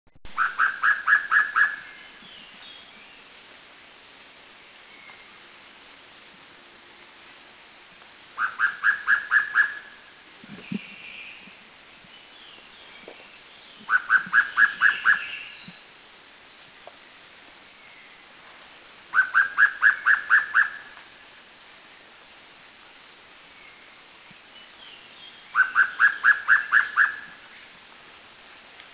B2A_SteeresPitta1Bohol210_SDW.mp3